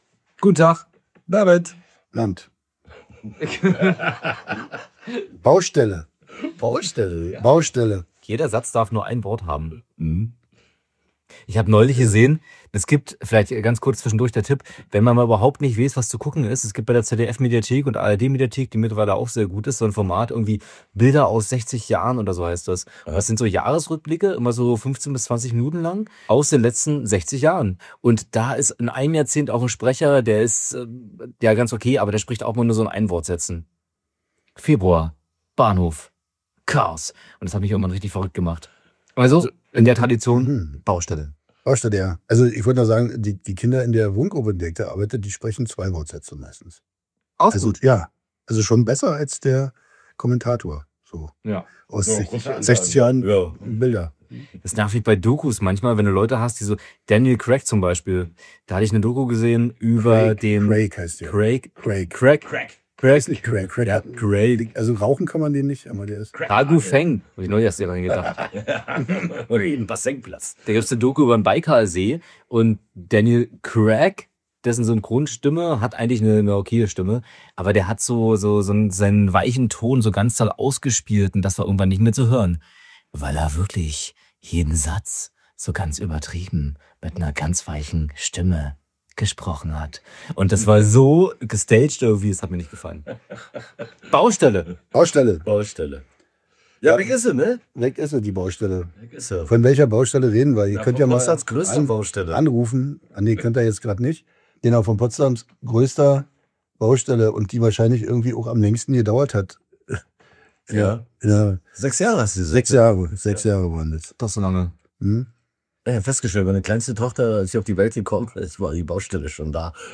Beschreibung vor 2 Monaten In der vorerst letzten Folgen kommt nochmal alles vor, was diesen Podcast groß gemacht hat (hihi, "groß gemacht"): Minutenlange Gespräche über Zugklos, fröhliches gegenseitiges Unterbrechen, unvollständige Anekdoten aus der Vergangenheit, fragmentiertes Vordergrundwissen über Potsdam schönste Baustelle, vorsichtig-fundierte Kritik am einseitigen Wiederaufbau und ein Quiz zum Miträtseln: Jetzt ist eh egal!